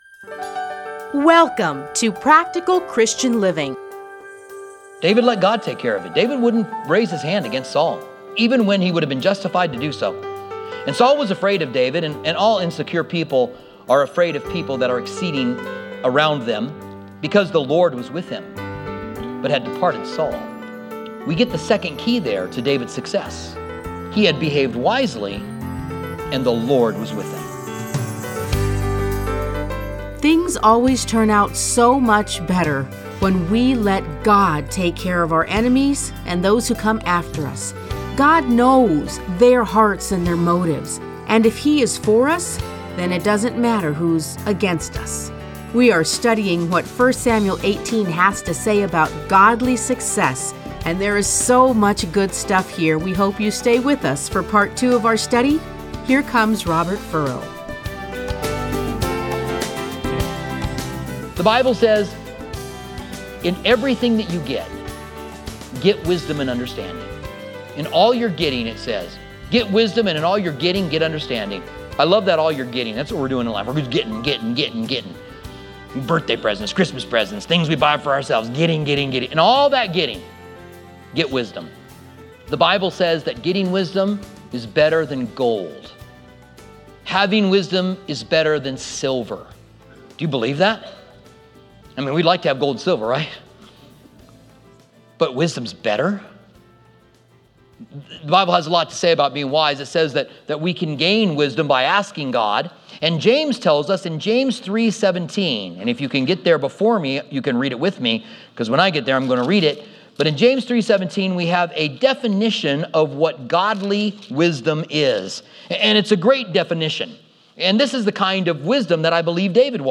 Listen to a teaching from 1 Samuel 18:1-30.